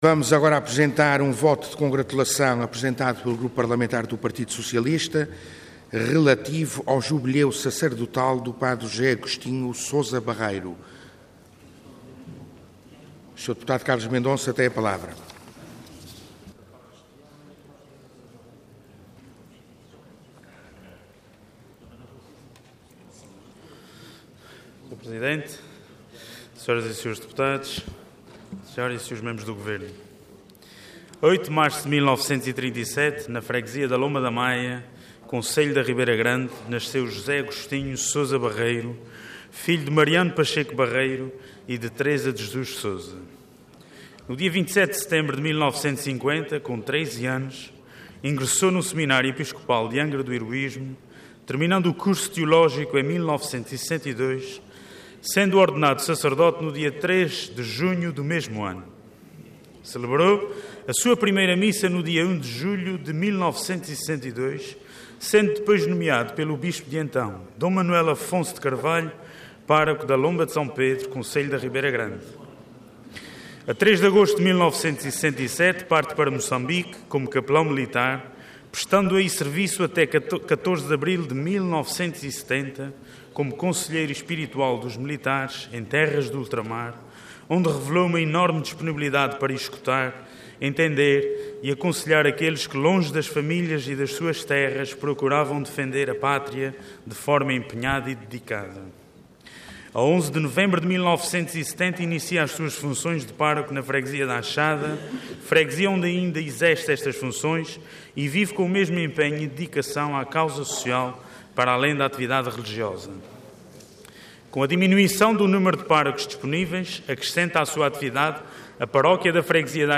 Website da Assembleia Legislativa da Região Autónoma dos Açores
Intervenção Voto de Congratulação Orador Carlos Mendonça Cargo Deputado Entidade PS